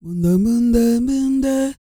E-CROON 3004.wav